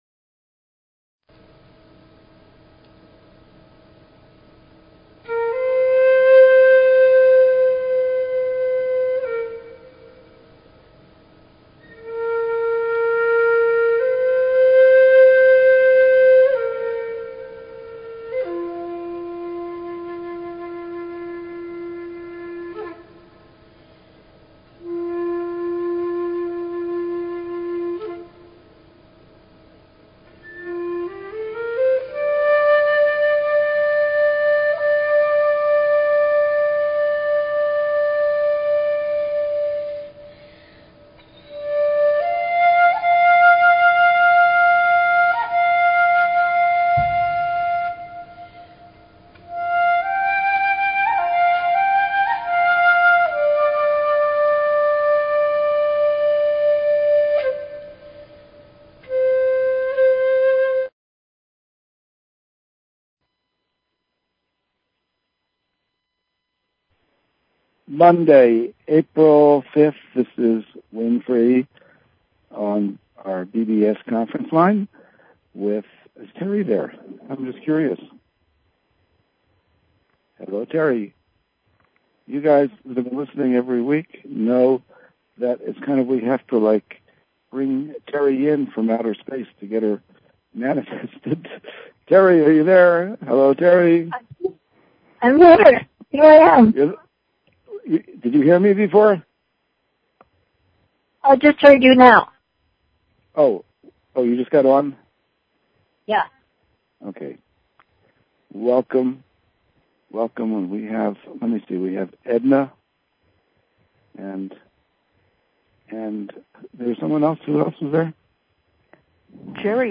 Talk Show Episode
The Elohim share how nodes of energy/frequency created space and time where none existed previously. As they communicated this information they connected up with the energies of listeners and at the end of the show, people had the opportunity to make silent requests.